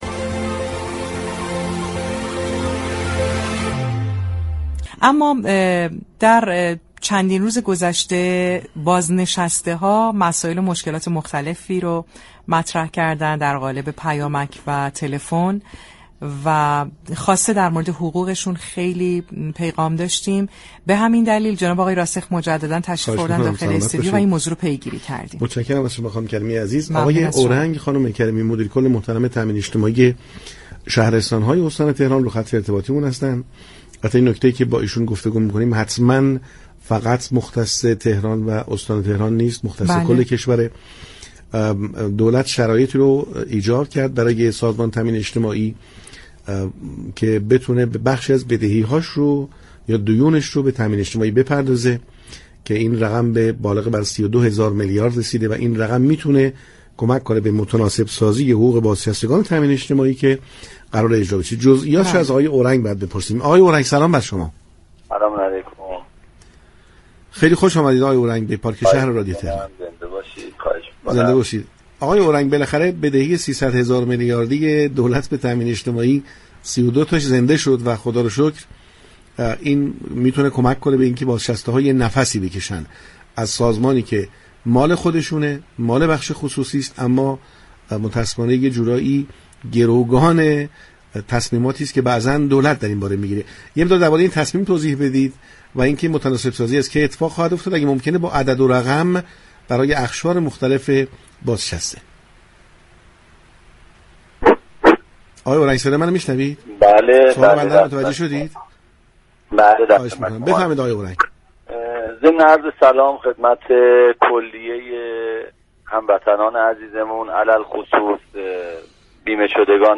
مدیركل تامین اجتماعی شهرستان‌های استان تهران مهمان پارك شهر رادیو تهران شد تا در ارتباط با جزئیات افزایش حقوق بازنشستگان تامین اجتماعی و متناسب سازی در این حوزه صحبت كند.